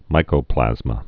(mīkō-plăzmə)